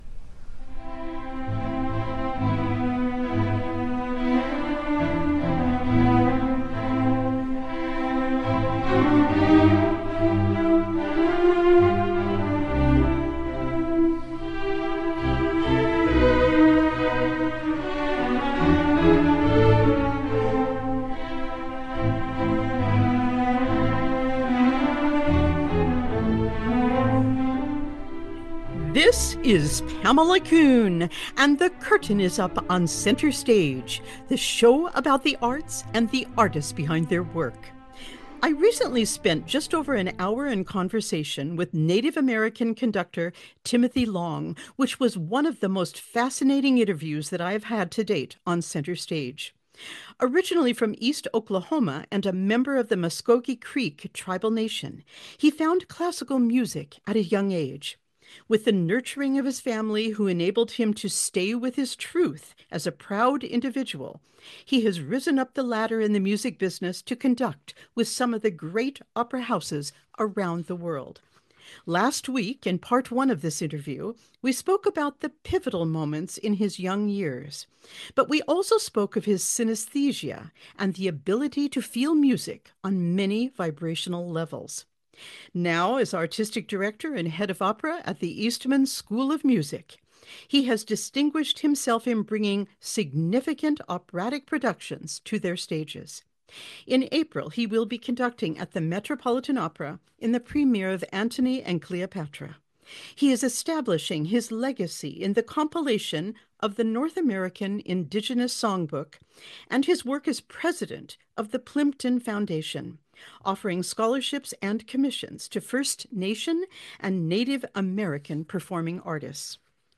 We discuss their art, current projects, upcoming performances, exhibitions or releases, and what it means to be an artist in the 21st century.
Conversations